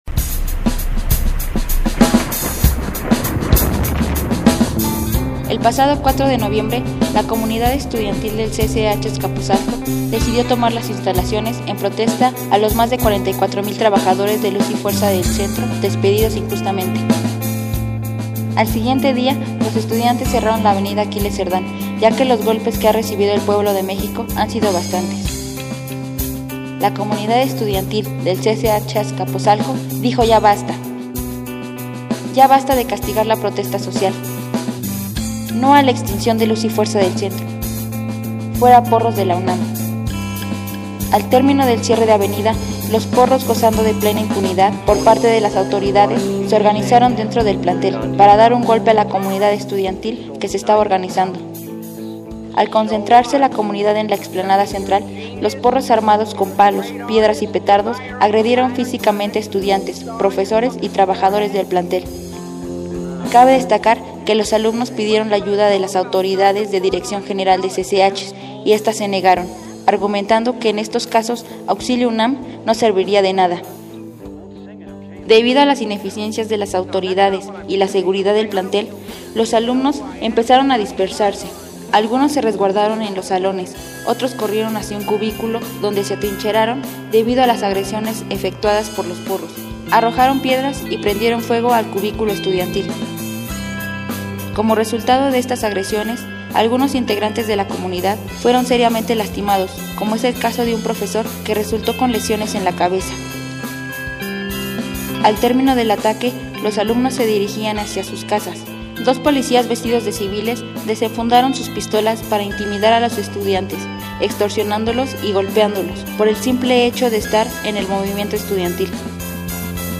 58_AudioReporte_ataques_en_cch_azcapotzalco.mp3